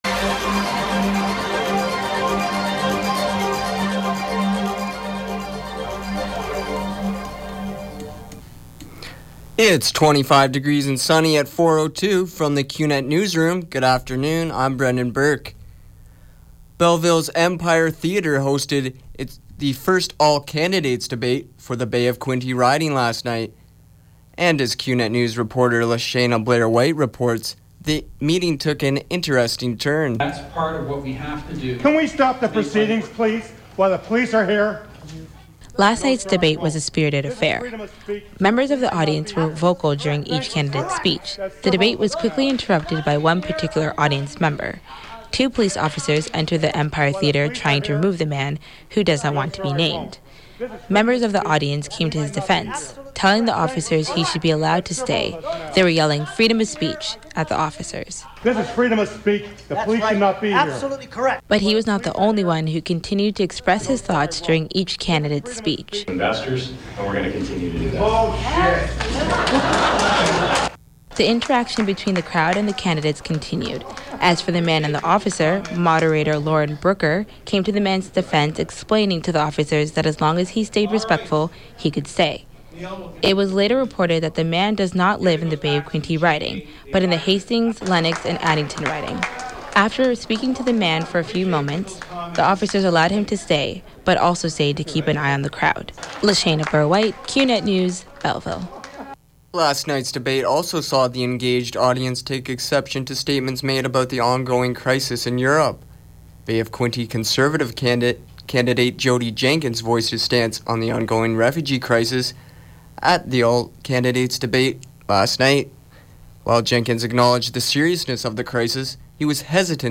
91X newscast – Thursday, Sept. 17, 2015 – 4 p.m.